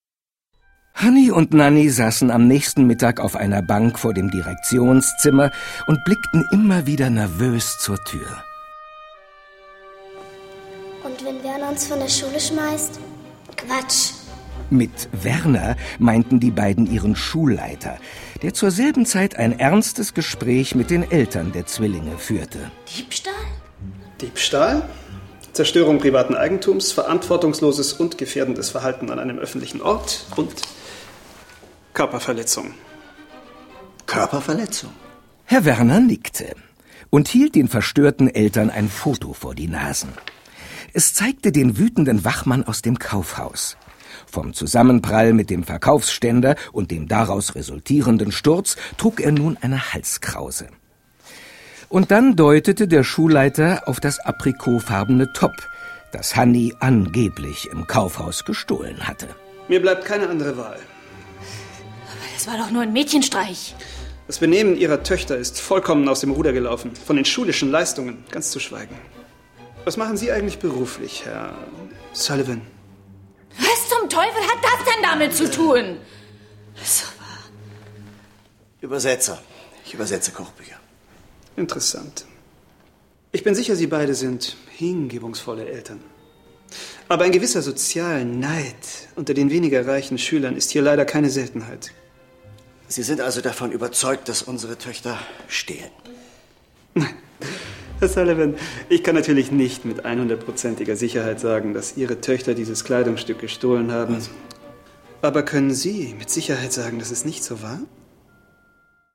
Hanni und Nanni - Das Original-Hörspiel zum Film 1